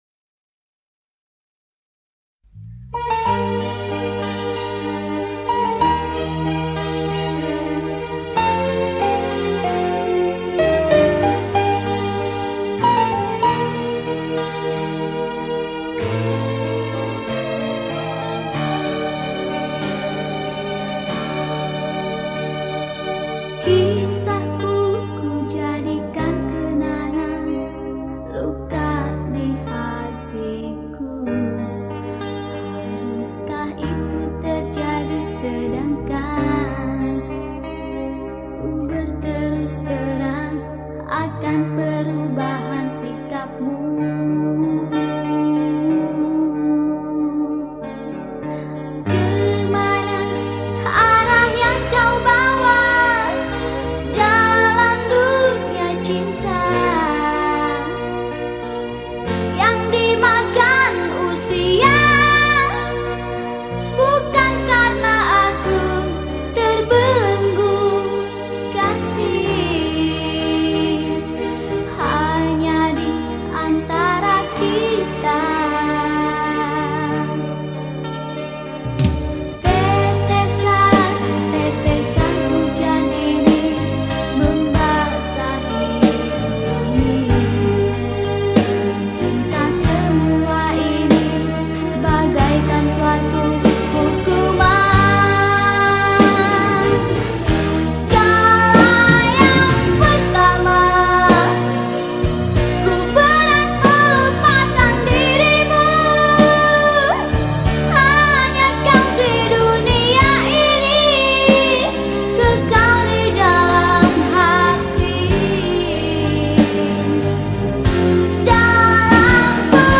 penyanyi dangdut